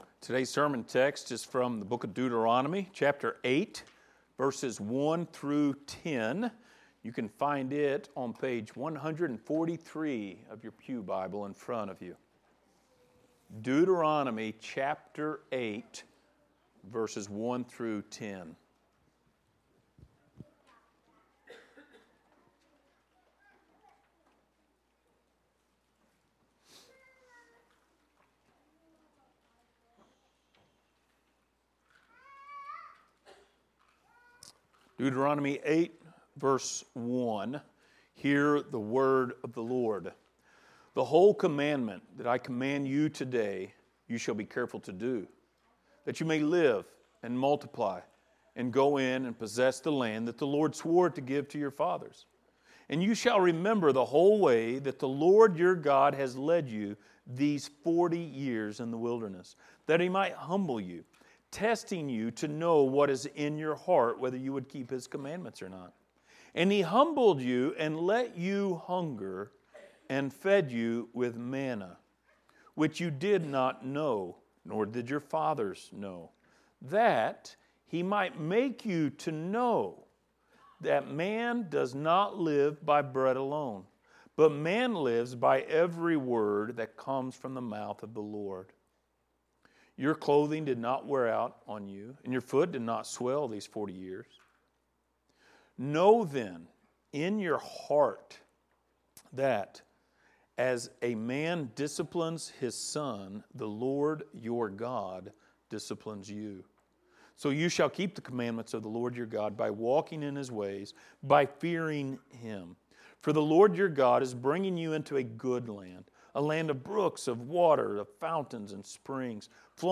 Passage: Deuteronomy 8:1-10 Service Type: Sunday Morning